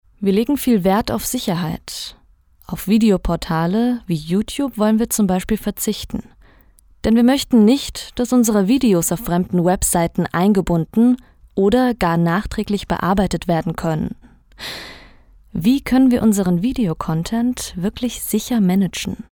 deutsche Sprecherin
mittel tiefe Stimme, warme Klangfarbe
Sprechprobe: eLearning (Muttersprache):